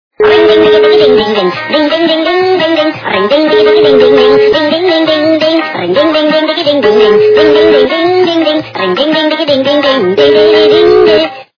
фильмы и телепередач
При заказе вы получаете реалтон без искажений.